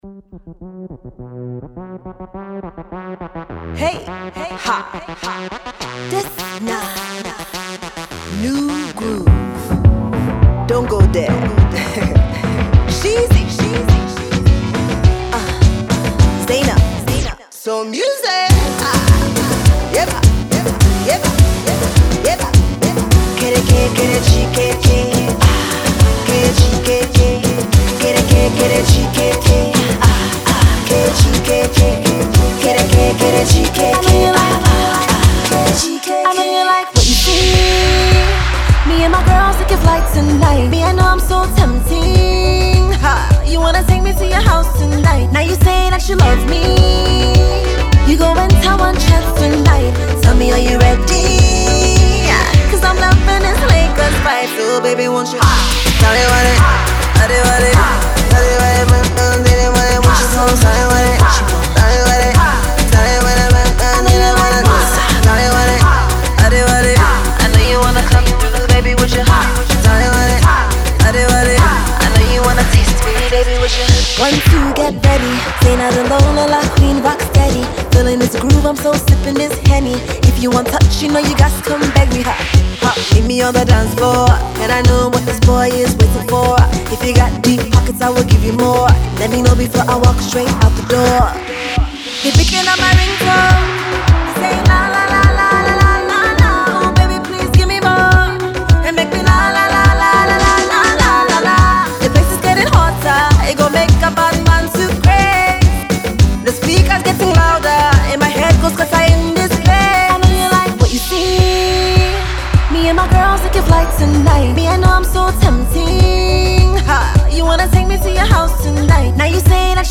an Afrobeat inspired track laced with an oriental spin